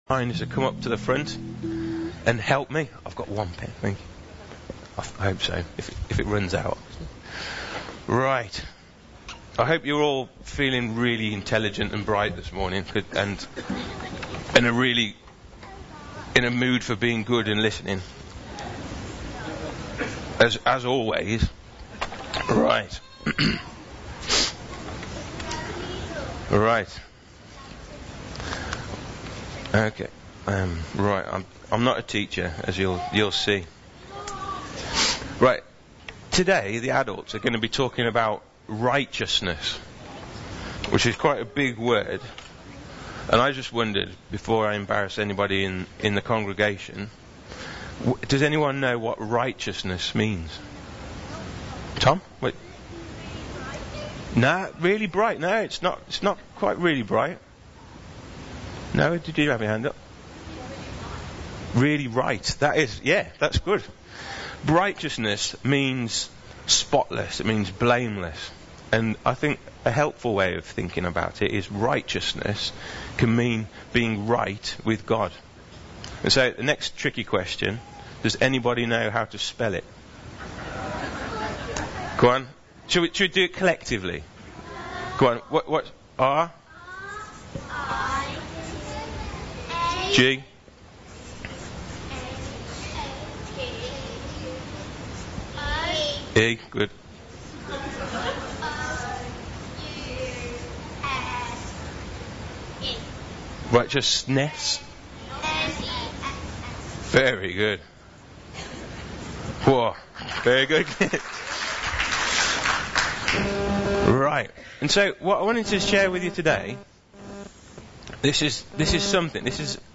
Jeremiah_Righteousness_Children’s Talk